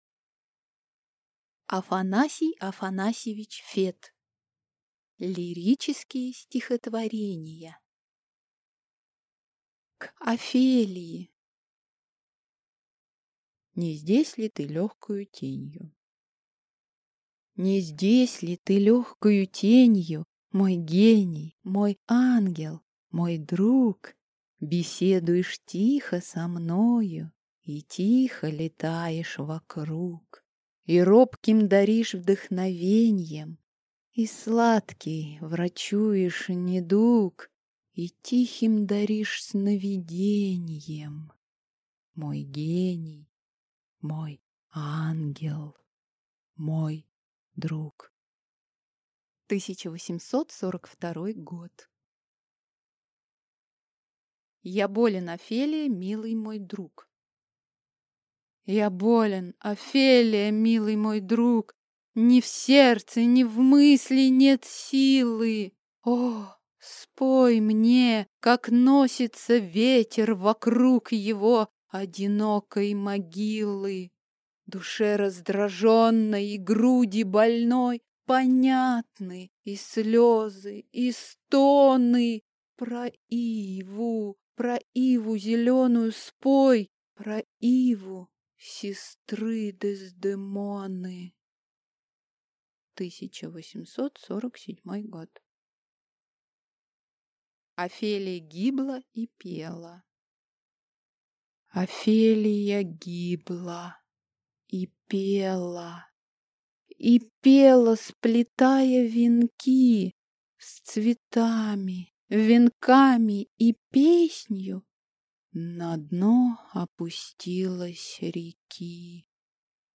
Аудиокнига Лирические стихотворения | Библиотека аудиокниг